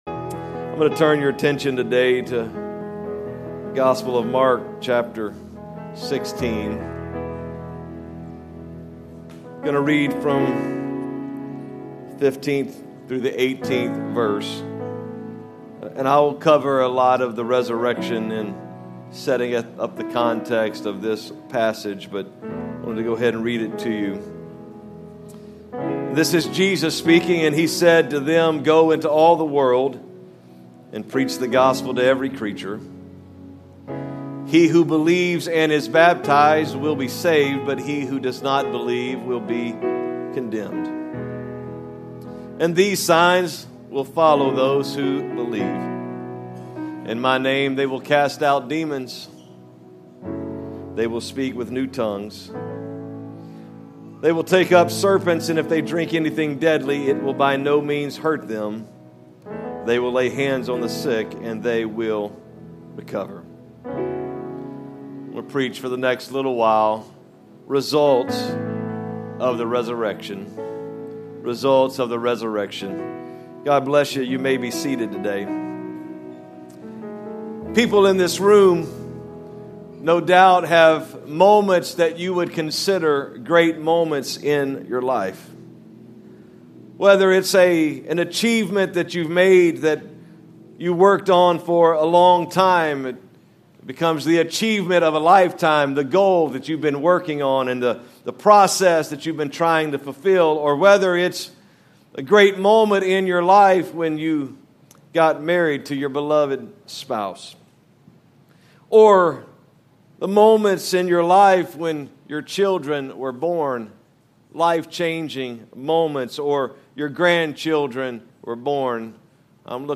For notes for this sermon, visit: Results of The Resurrection